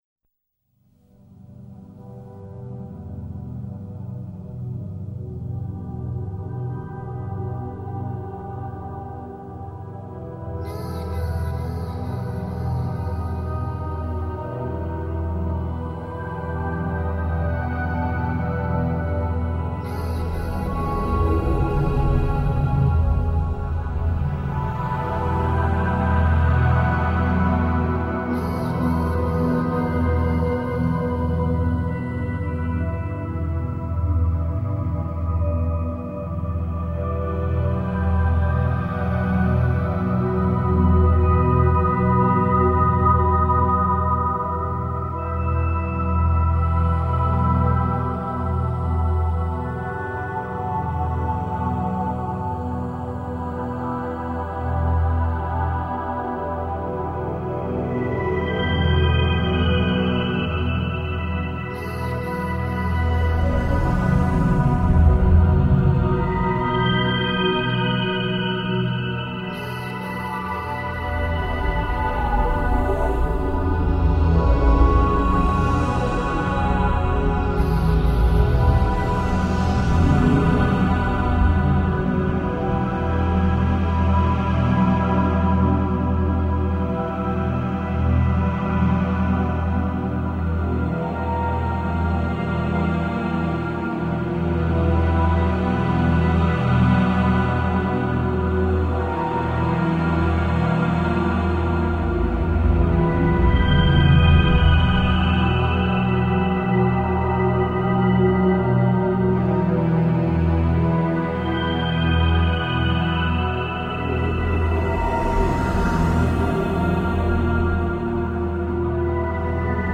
◆ 音乐类别:电影原声